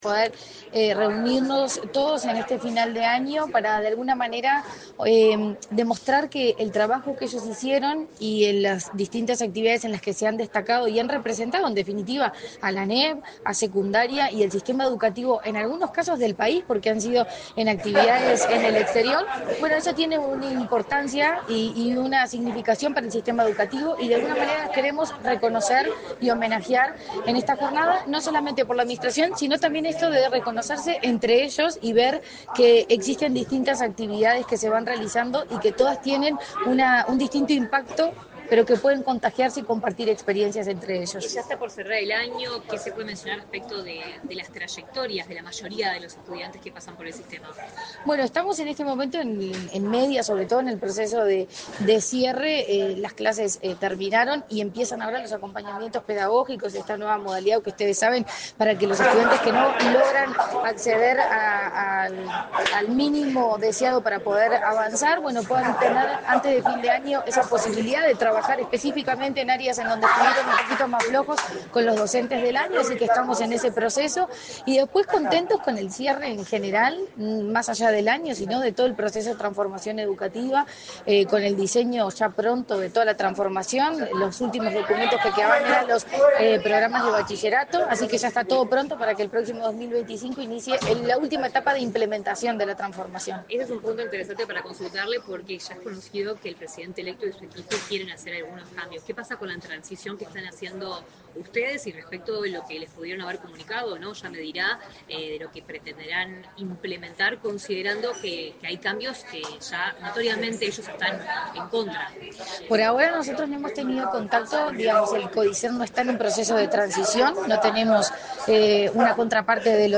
Declaraciones de la presidenta de ANEP, Virginia Cáceres
Declaraciones de la presidenta de ANEP, Virginia Cáceres 09/12/2024 Compartir Facebook X Copiar enlace WhatsApp LinkedIn Tras el evento Secundaria Celebra, reconocimiento a estudiantes destacados de todo el país, este 9 de diciembre, la presidenta de la Administración Nacional de Educación Pública (ANEP), Virginia Cáceres, realizó declaraciones a la prensa.